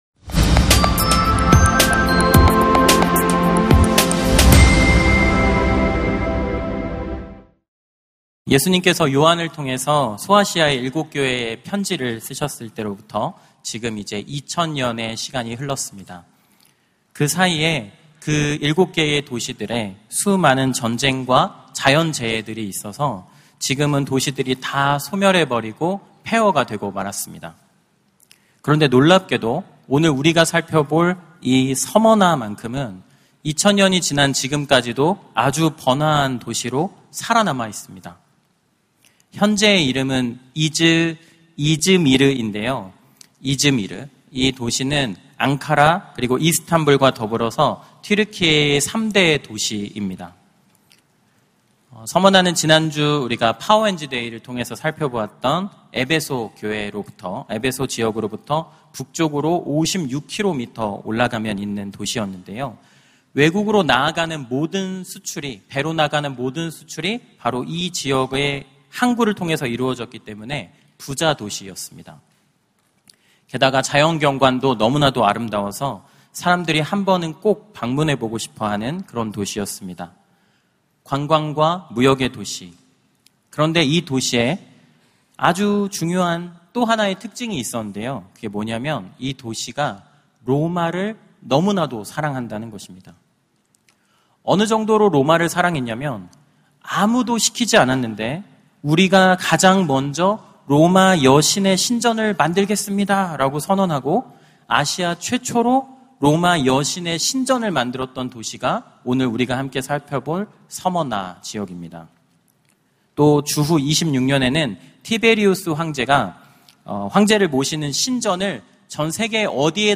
설교 : 젊은이예배(홀리웨이브)